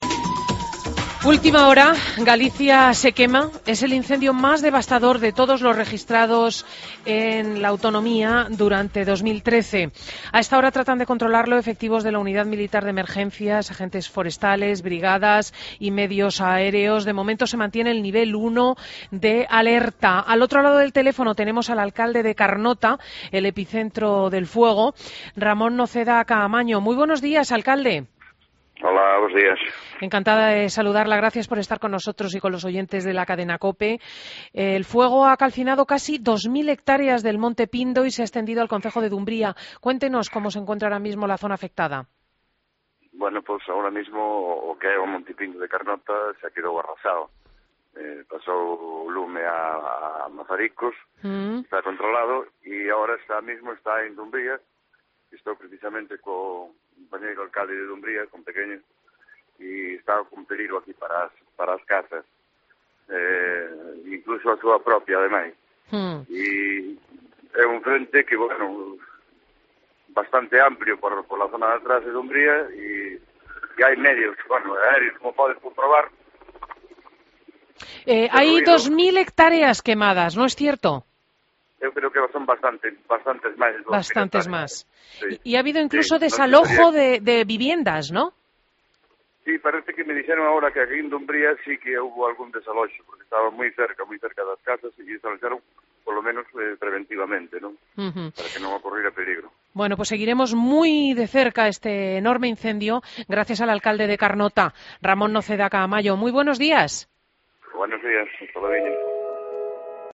Entrevista a Ramón Noceda, Alcalde de Carnota en "Fin de Semana" de COPE.